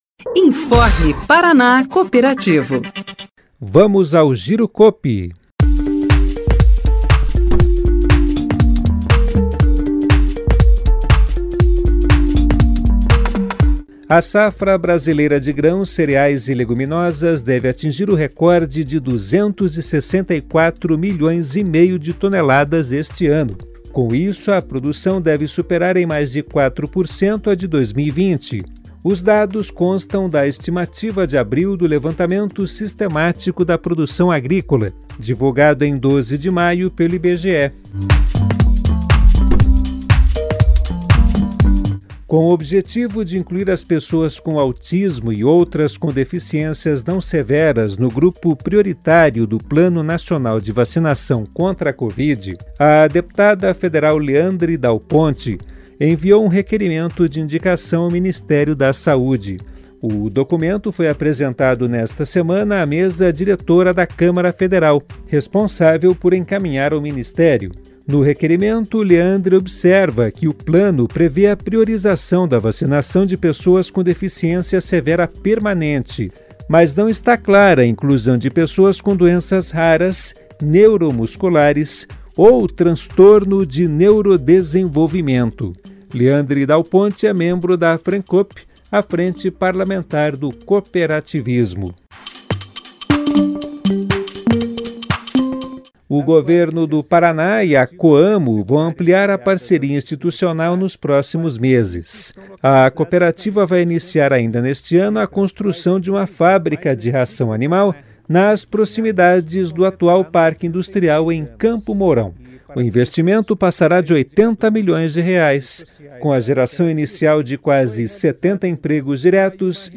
Notícias Rádio Paraná Cooperativo